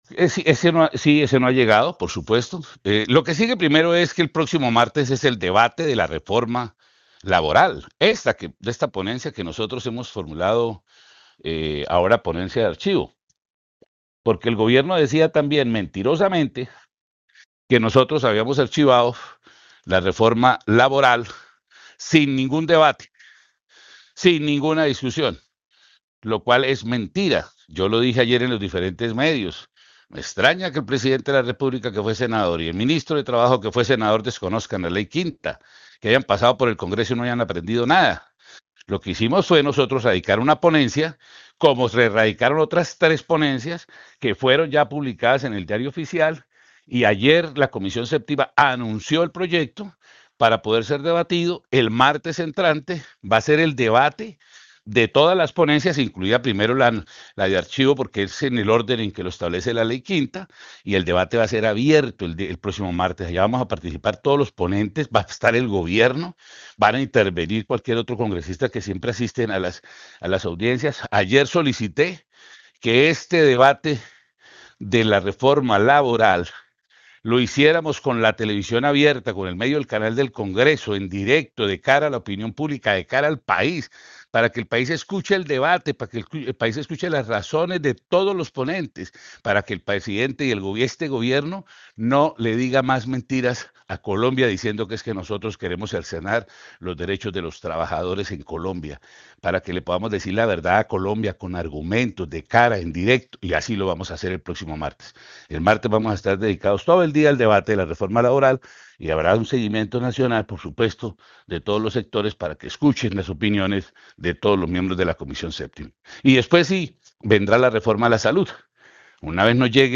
Miguel Ángel Pinto, Senador de la República de Colombia
El congresista liberal Miguel Ángel Pinto, quien dio ponencia de archivo a la iniciativa del ejecutivo, aseguró en Caracol Radio que la comisión séptima debatirá otros tres conceptos con relación al proyecto de ley.